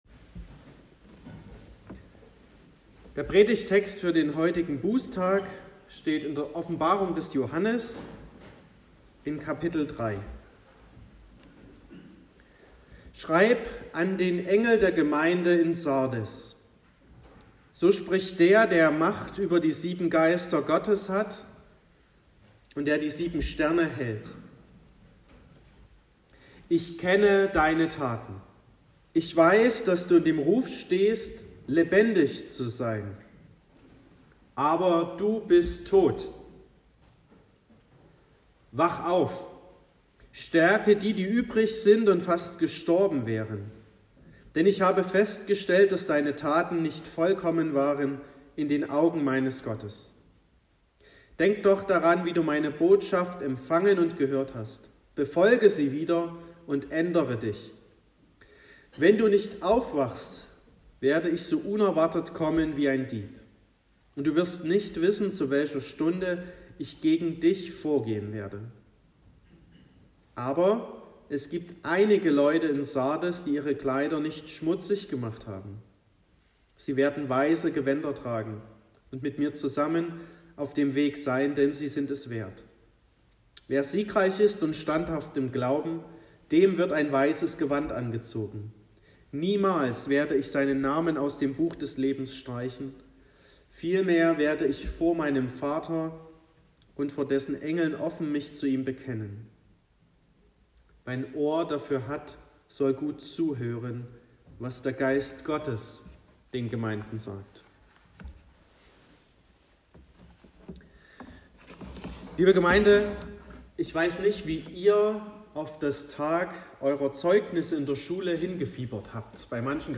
16.11.2022 – Gottesdienst